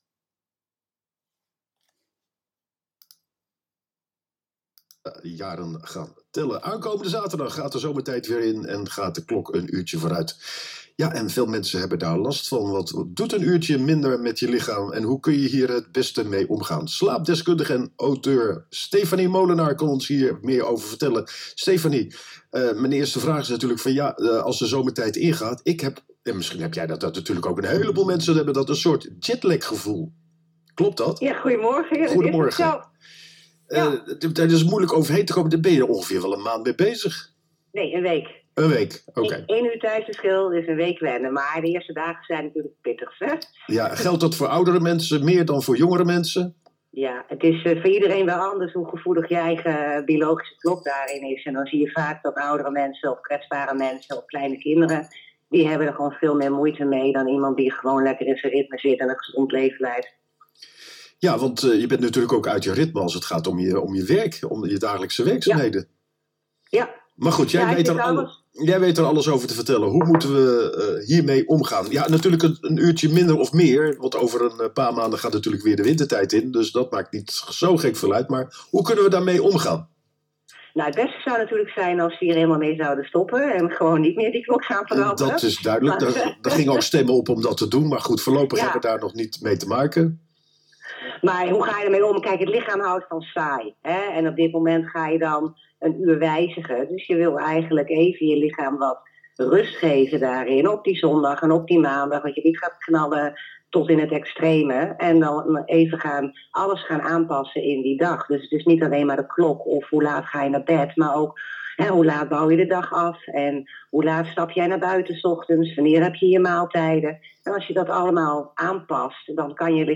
Zomertijd, alle leeftijden (radio)
Zomertijd, tips voor jonge kindjes Naar WIJ (Artikel) Zomertijd, wat je kunt doen Naar Insta (slider post) Zomertijd, slaapissues (uitleg) Naar Insta (slider post) Zomertijd, alle leeftijden (radio) Luister naar het interview